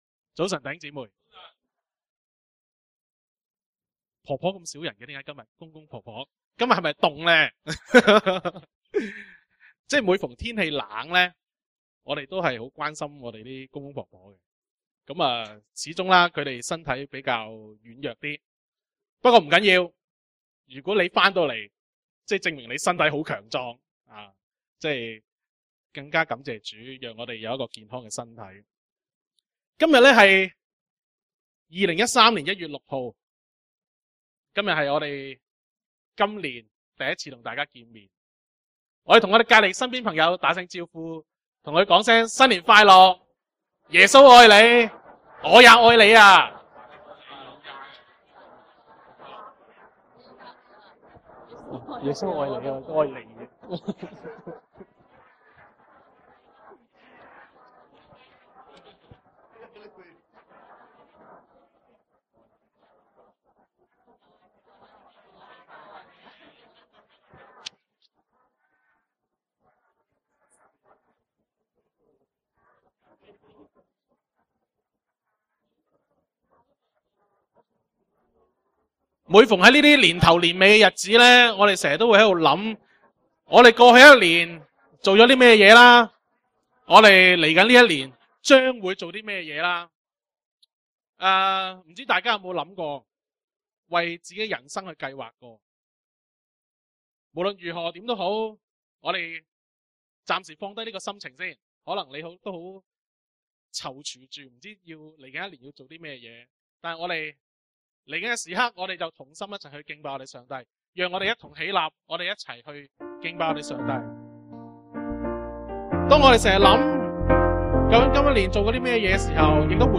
Posted by admin on 三月 01 2013 | 下載, 詩歌敬拜